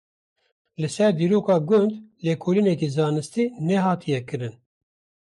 Pronounced as (IPA)
/ɡʊnd/